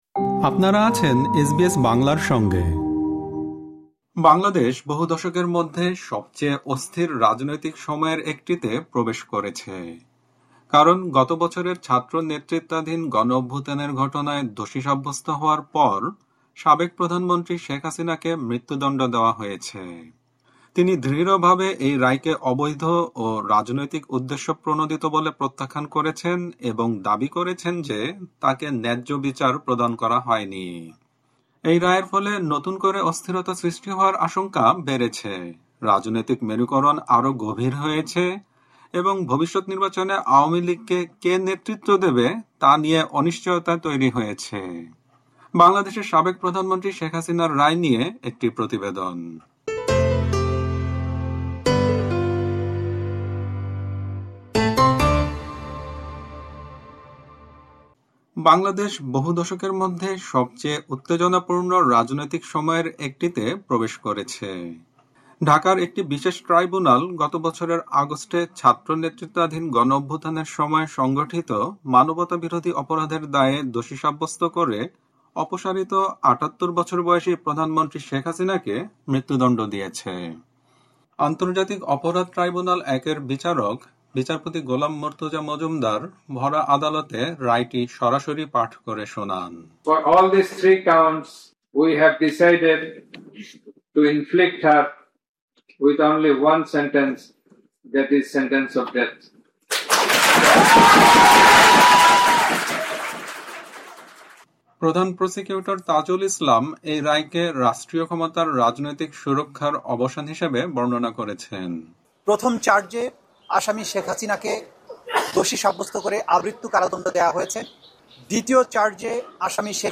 সম্পূর্ণ প্রতিবেদনটি শুনতে উপরের অডিও-প্লেয়ারটিতে ক্লিক করুন।